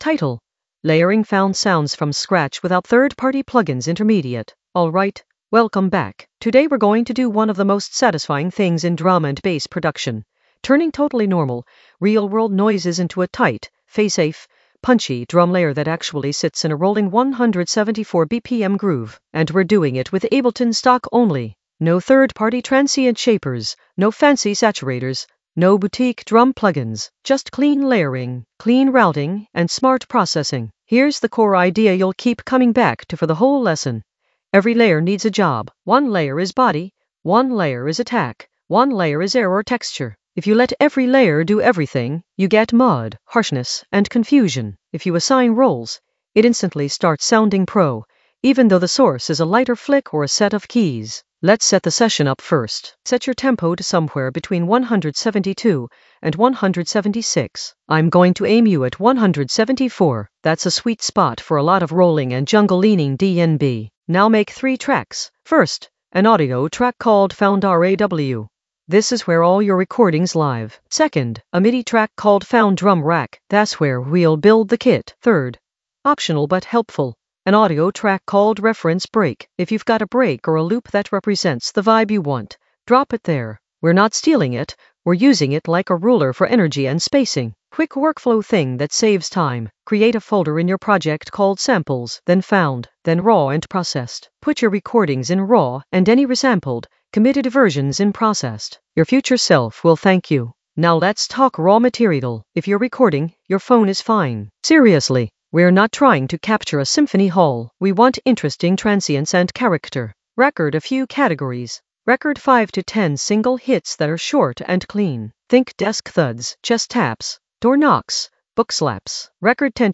Narrated lesson audio
The voice track includes the tutorial plus extra teacher commentary.
An AI-generated intermediate Ableton lesson focused on Layering found sounds from scratch without third-party plugins in the Sampling area of drum and bass production.